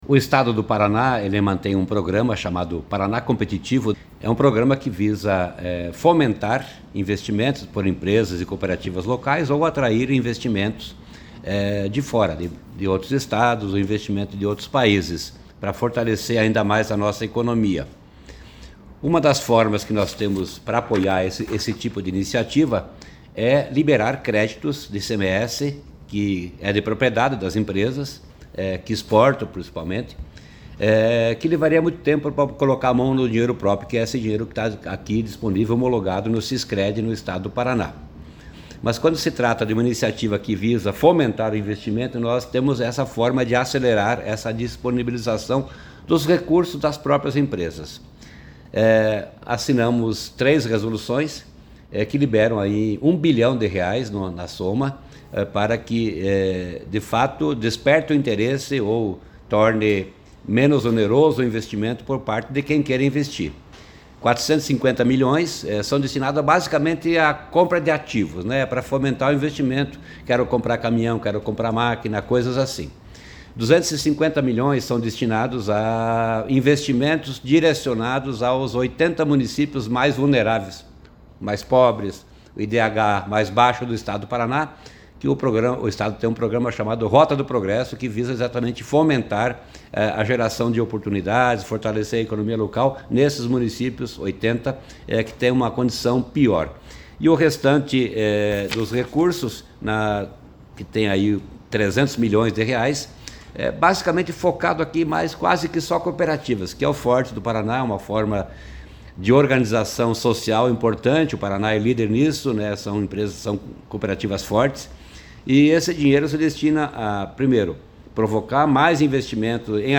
Sonora do secretário da Fazenda, Norberto Ortigara, sobre o programa Paraná Competitivo e as expectativas para 2025